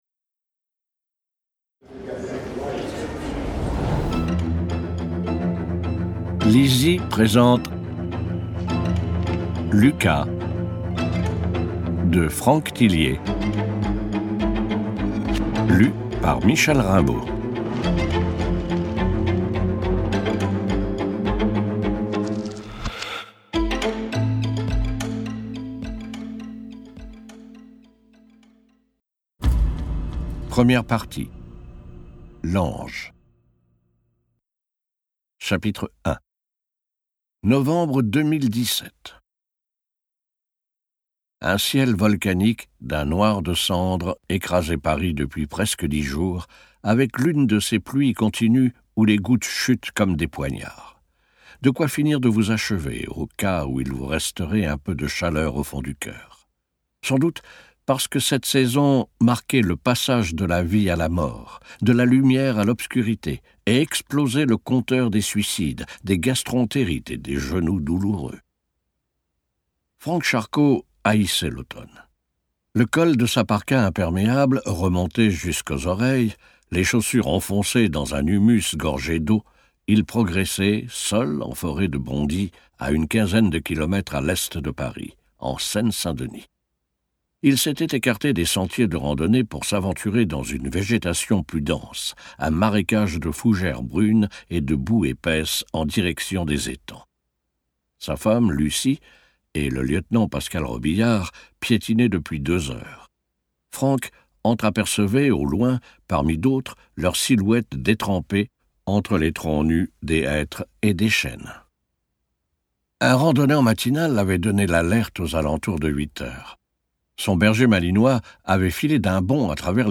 Extrait gratuit